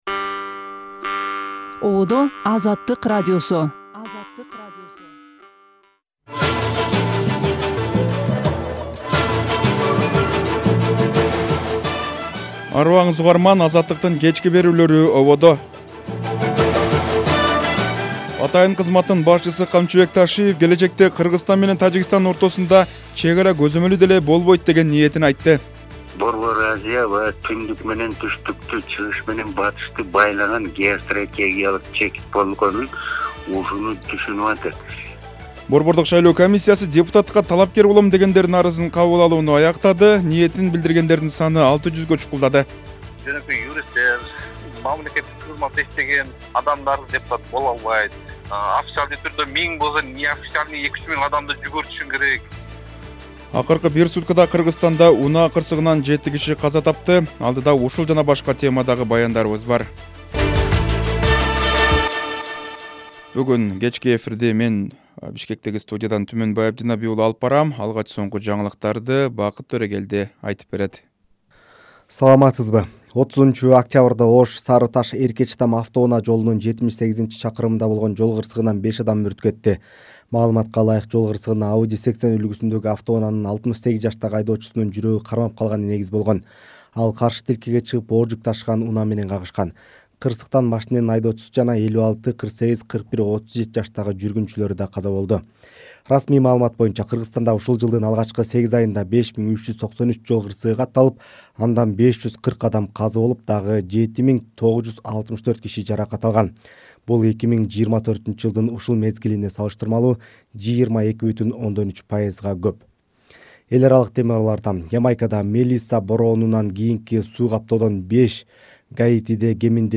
Жаңылыктар | 31.10.2025 | Бир суткада жети өлүм.